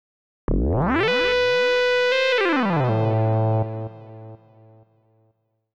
XTRA011_VOCAL_125_A_SC3.wav